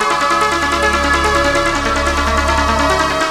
DISCO LOO01L.wav